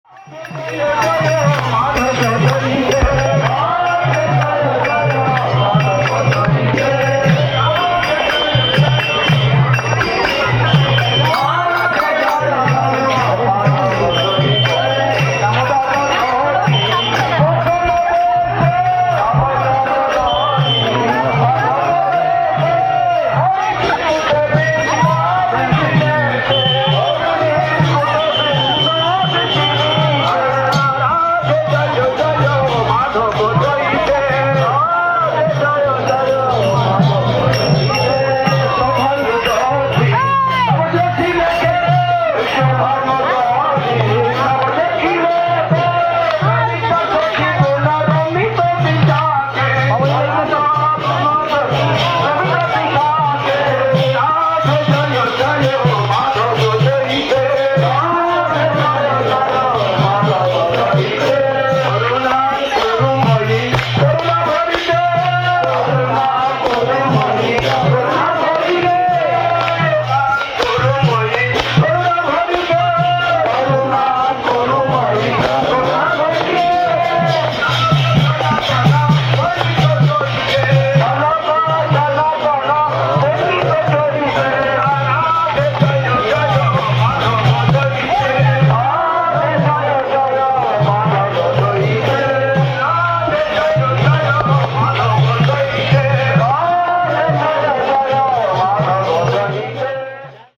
Place: SCSMath Nabadwip
Tags: Kirttan